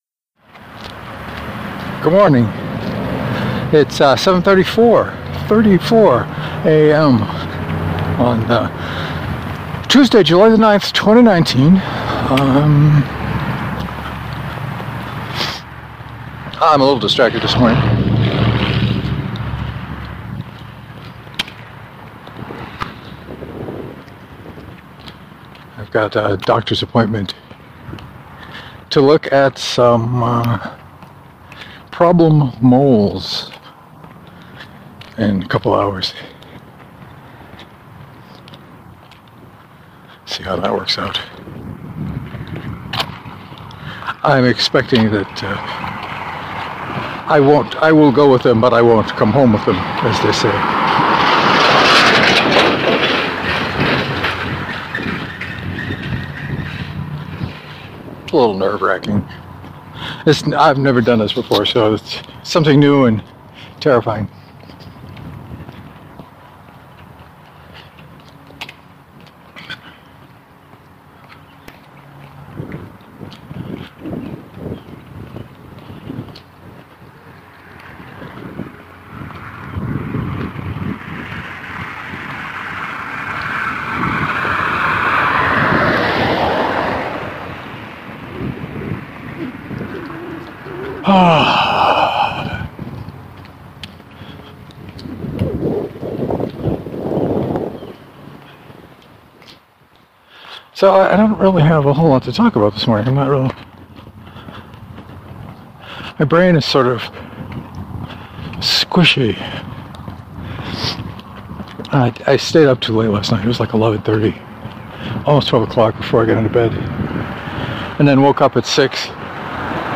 Lots of boot sounds. Not so much mouth sounds.